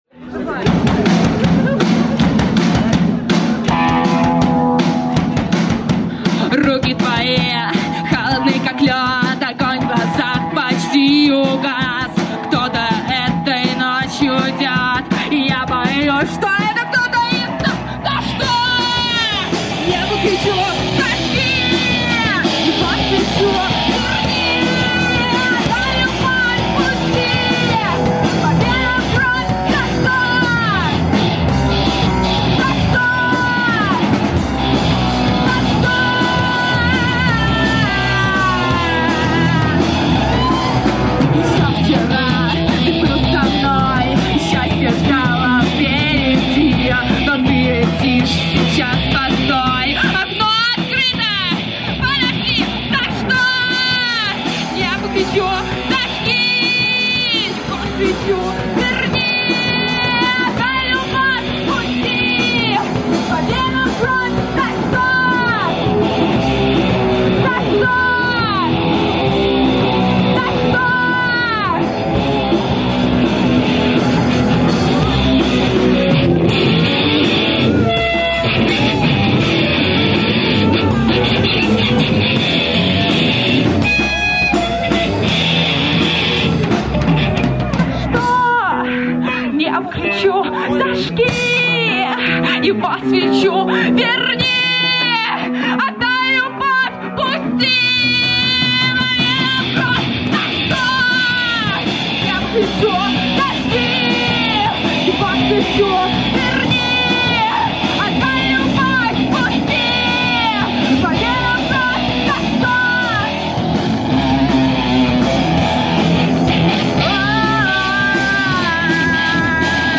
Live на крыльце (04.05.06)